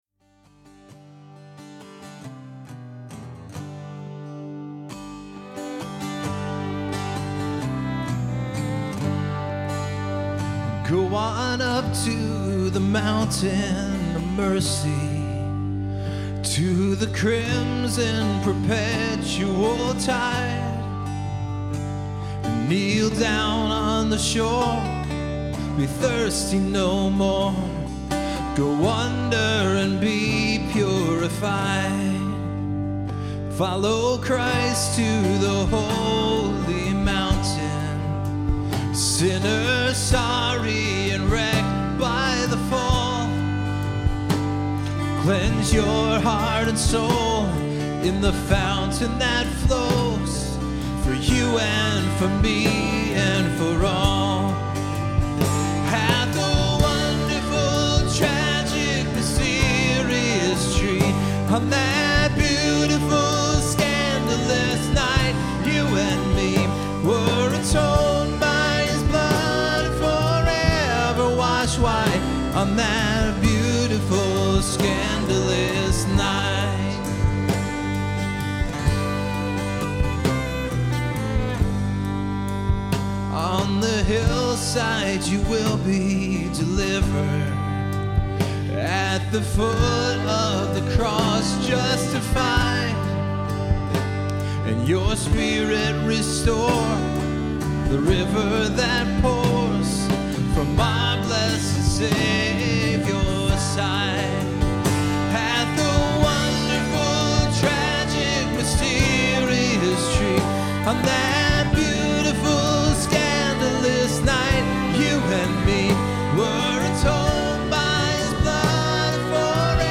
Performed live on Good Friday at Terra Nova - Troy on 3/21/08.